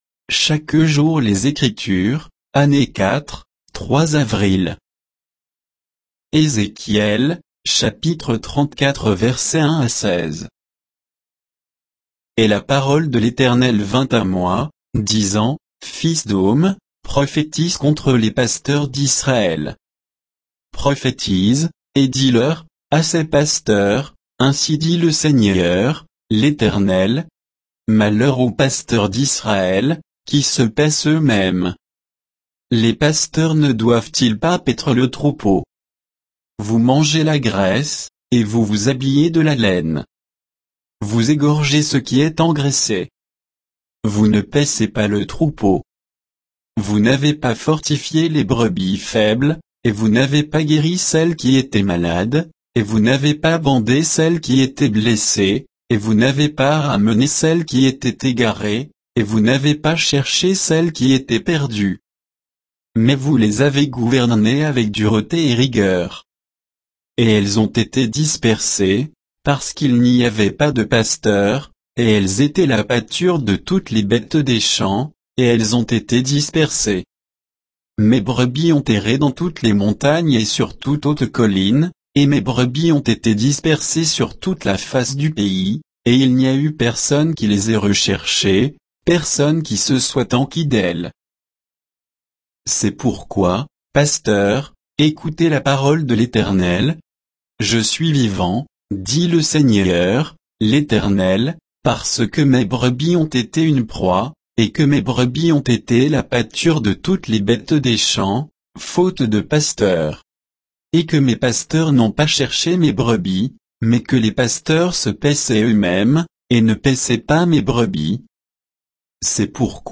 Méditation quoditienne de Chaque jour les Écritures sur Ézéchiel 34, 1 à 16